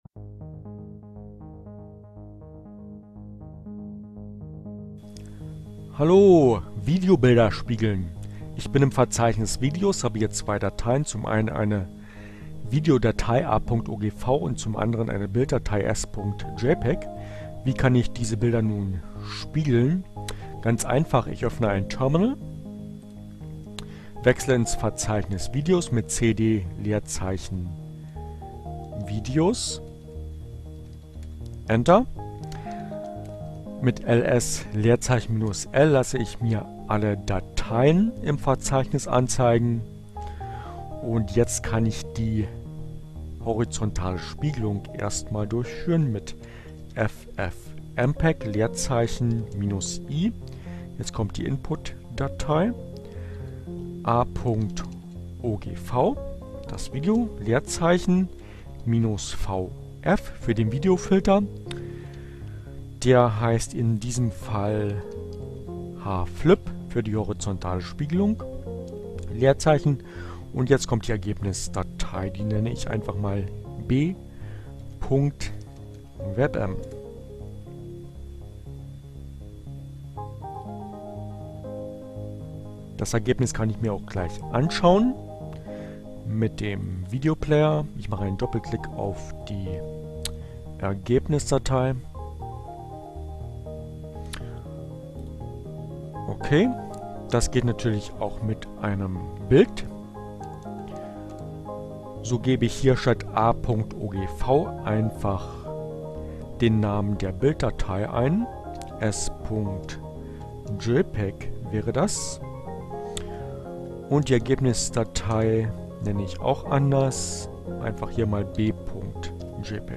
Tags: Fedora, Gnome, Linux, Neueinsteiger, Ogg Theora, ohne Musik, screencast, CC by, Gnome3, ffmpeg, Videobearbeitung, hflip, vflip